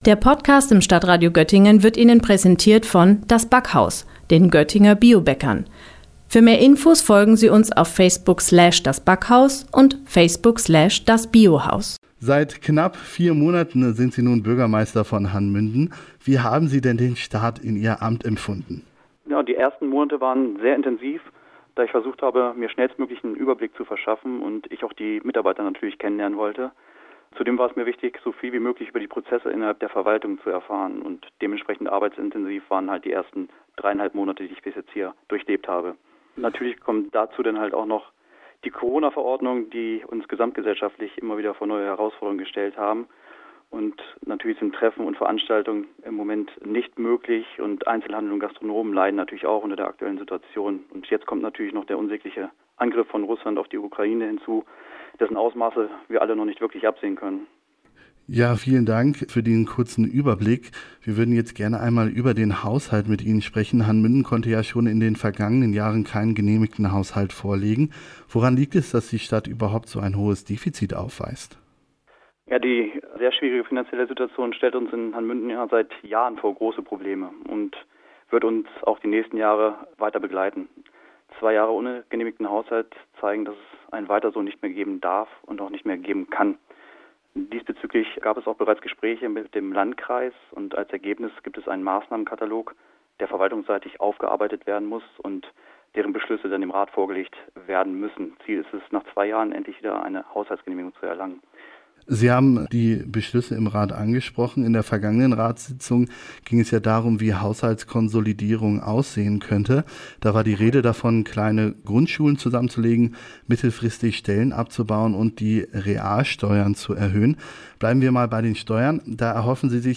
Mündener Haushalt – Bürgermeister Tobias Dannenberg über Sparzwang - StadtRadio Göttingen